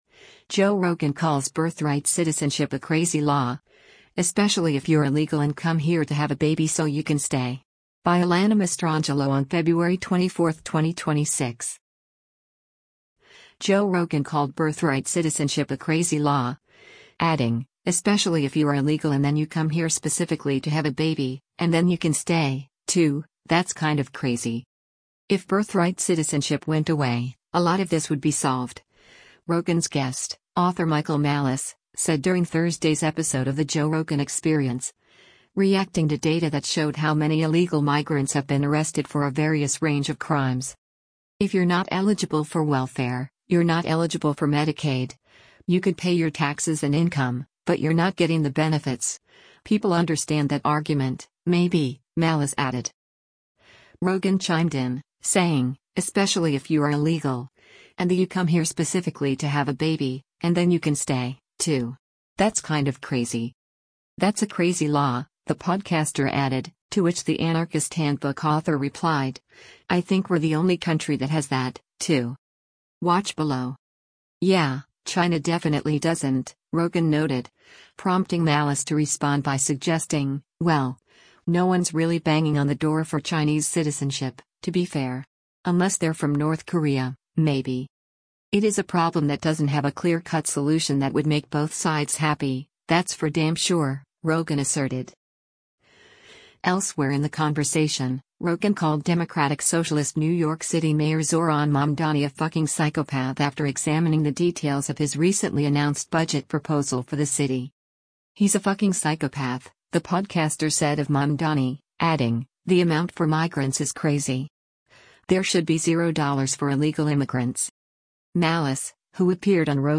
“If birthright citizenship went away, a lot of this would be solved,” Rogan’s guest, author Michael Malice, said during Thursday’s episode of The Joe Rogan Experience, reacting to data that showed how many illegal migrants have been arrested for a various range of crimes.